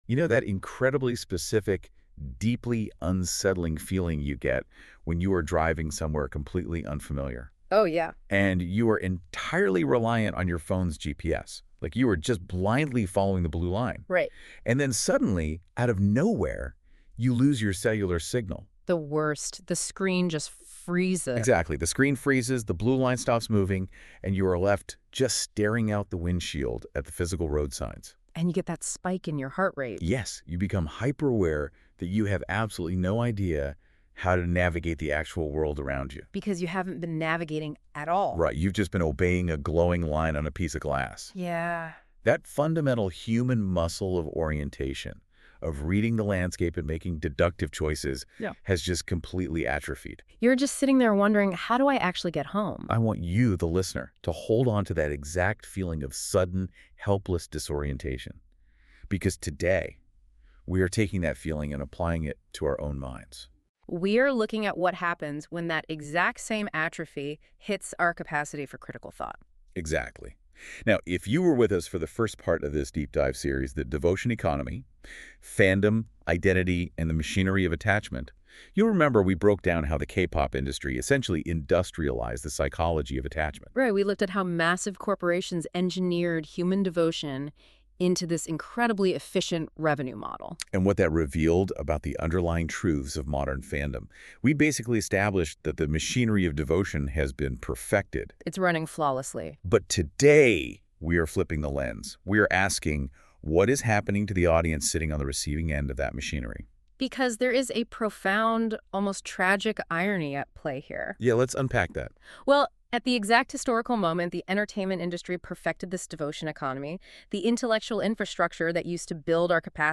Note : The podcast is generated from the following AI curated text.